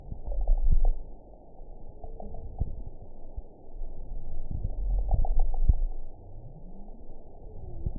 event 922004 date 12/25/24 time 01:25:48 GMT (5 months, 3 weeks ago) score 5.78 location TSS-AB03 detected by nrw target species NRW annotations +NRW Spectrogram: Frequency (kHz) vs. Time (s) audio not available .wav